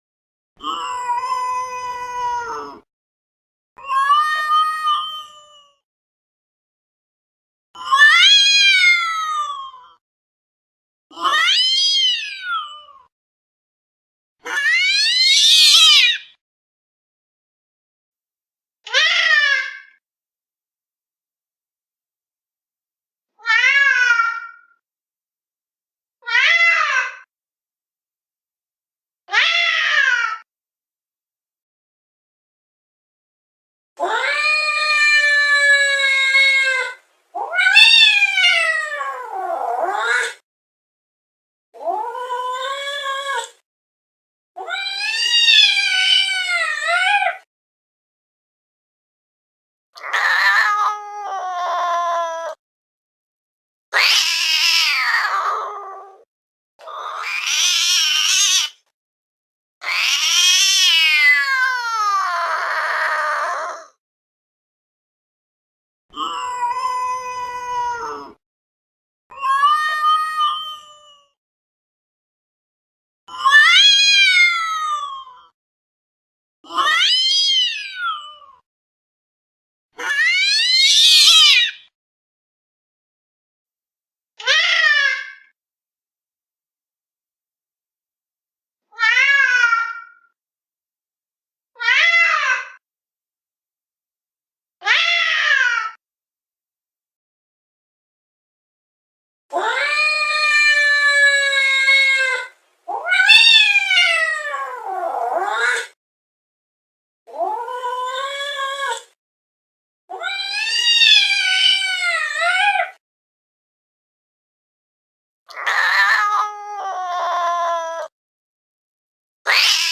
دانلود آهنگ جیغ گربه عصبانی از افکت صوتی انسان و موجودات زنده
دانلود صدای جیغ گربه عصبانی از ساعد نیوز با لینک مستقیم و کیفیت بالا
جلوه های صوتی